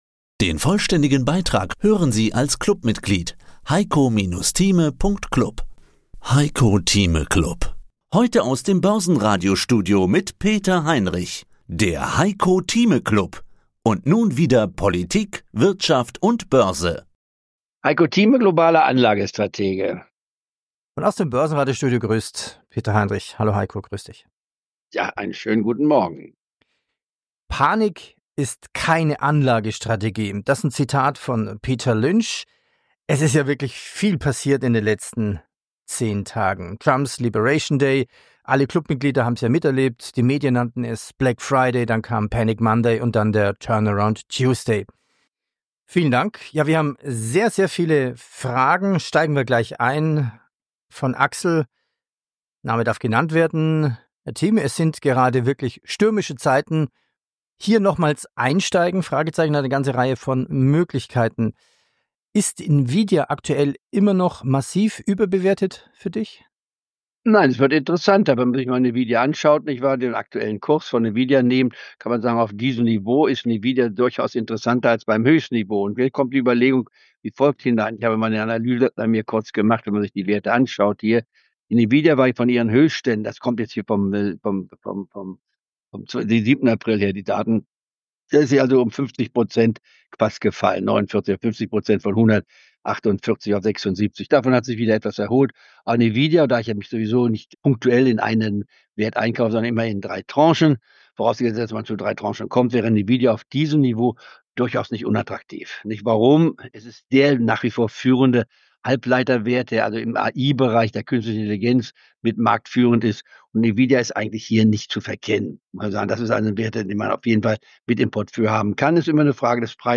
Im Heiko Thieme Club hören Sie ein mal wöchentlich ein exklusives Interview zum aktuellen Börsengeschehen, Einschätzung der Marktlage, Erklärungen wie die Börse funktioniert oder Analysen zu einzelnen Aktienwerten. Themenschwerpunkte sind deutsche und amerikanische Aktienwerte sowie die Indizes der DAX-Familie und der Blick auf die Wall Street.